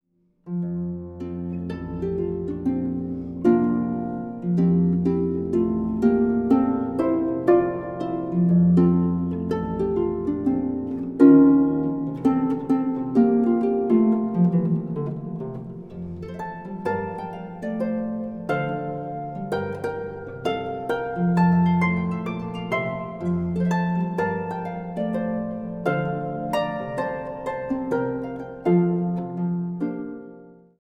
kerst-cd